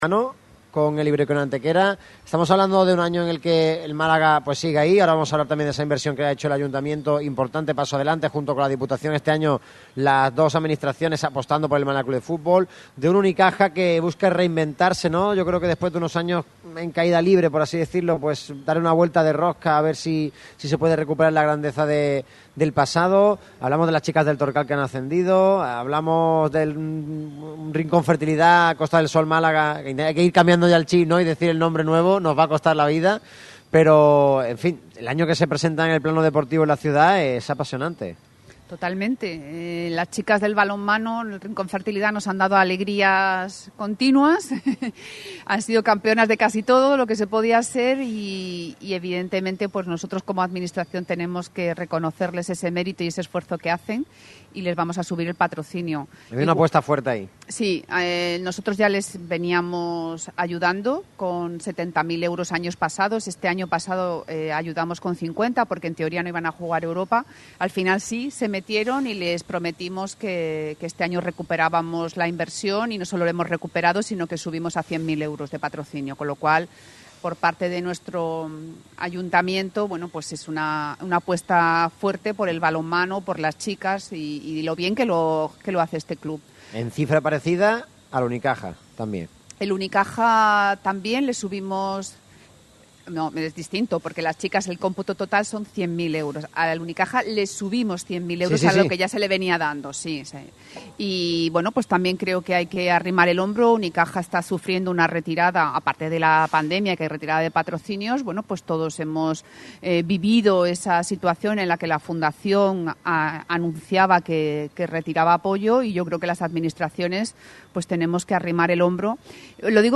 Radio Marca Málaga disfrutó de la presencia en su programa, celebrado en la Fábrica de Cervezas Victoria, de Noelia Losada, concejala de Cultura y Deporte del Ayuntamiento de Málaga